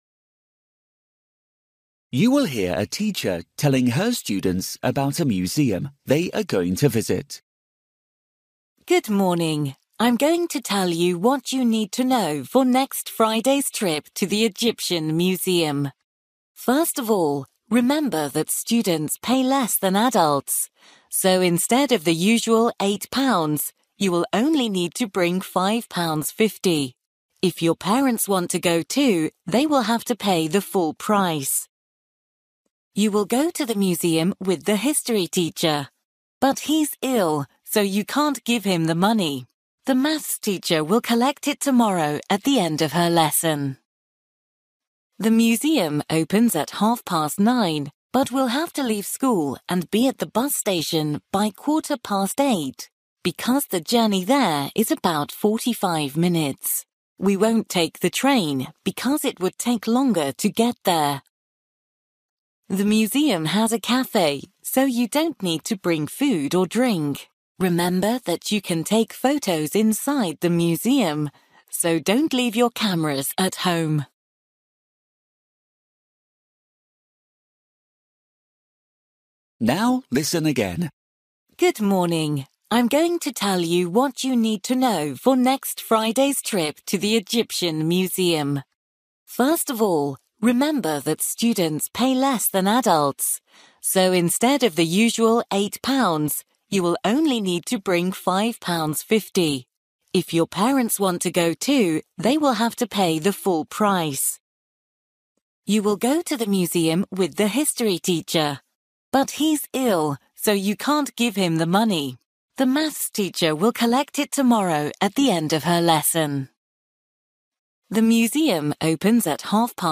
You will hear a teacher telling her students about a museum they are going to visit.